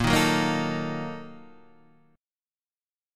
A# Major 7th Suspended 4th Sharp 5th